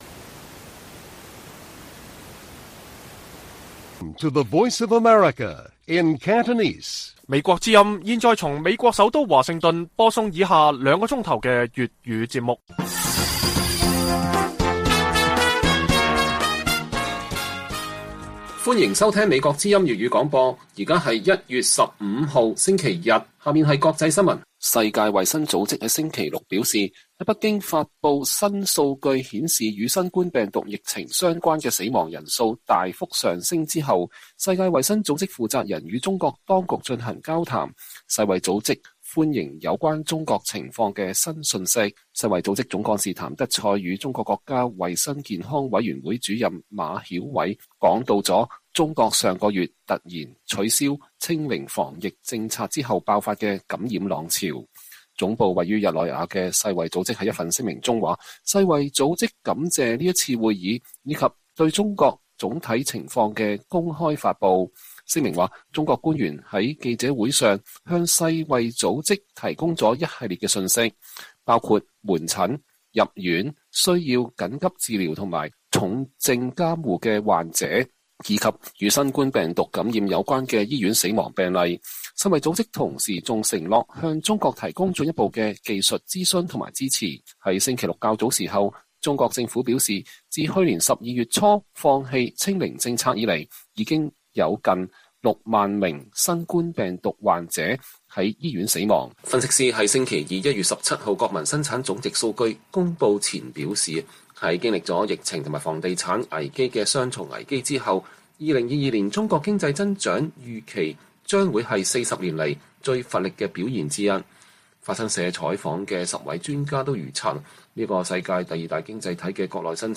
粵語新聞 晚上9-10點 ： 中國疫情數字假得離譜 除了高級黑簡直無法解釋